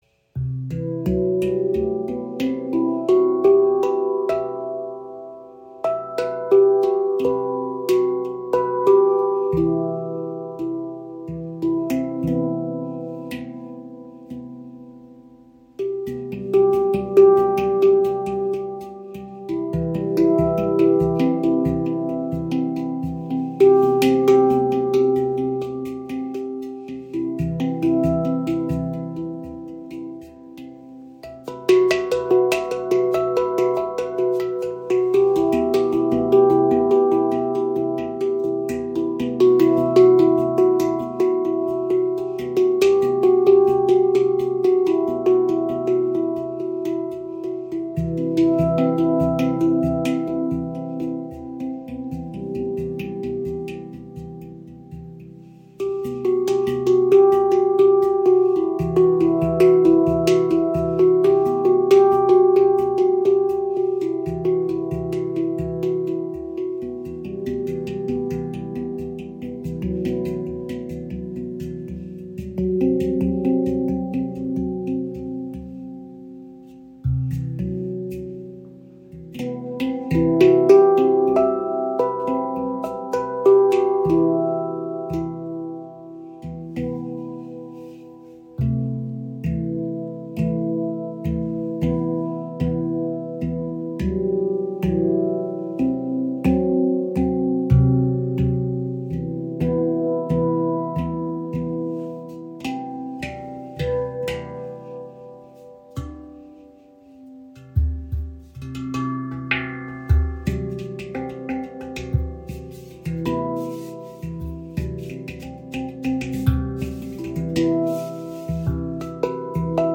Handpan ShaktiPan | C Aegean | 12 Felder – sanft & geheimnisvoll
• Icon Sanft schwebender Klang für Meditation und Entspannung
Die C Aegean-Stimmung 12 umfasst die Töne: C – E (F#) G (A) B C E F# G B E. Diese 12-Klangfelder-Skala erzeugt einen sanften, schwebenden Klang und lädt zu meditativen Klangreisen, ruhigen Improvisationen und intuitivem Spiel ein.
Gefertigt aus Ember Steel, überzeugt die ShaktiPan durch eine warme, lange tragende Schwingung und einen besonders weichen, sensiblen Anschlag.
Sie vereint die Klarheit von Dur mit einer subtilen, geheimnisvollen Tiefe und lädt zu intuitivem, gefühlvollem Spiel ein.